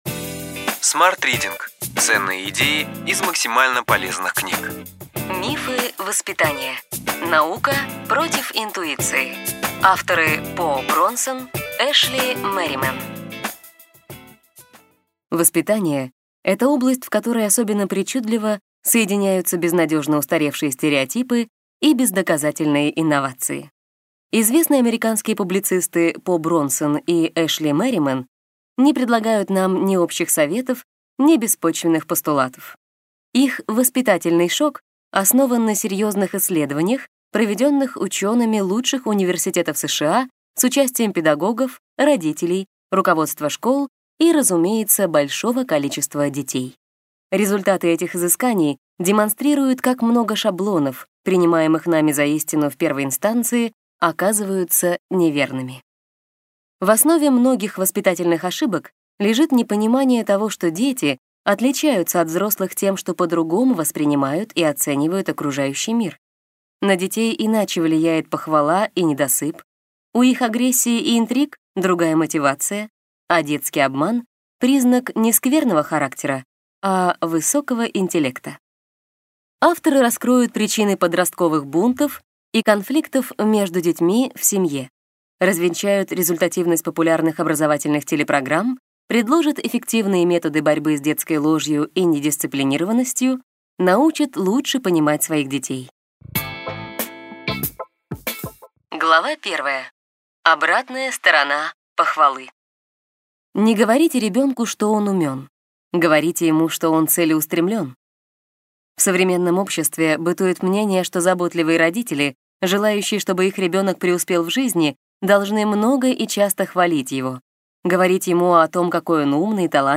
Аудиокнига Ключевые идеи книги: Мифы воспитания. Наука против интуиции.